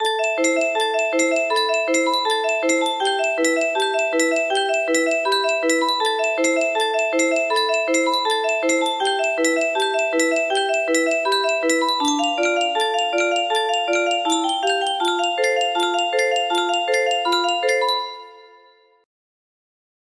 Custom Tune music box melody
Wow! It seems like this melody can be played offline on a 15 note paper strip music box!